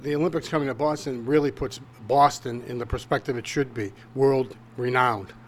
THIS MAN IS IN FAVOR OF GAMES COMING TO BOSTON.
CUT-4-MAN-IN-FAVOR-OF-GAMES-COMING-TO-BOSTON.mp3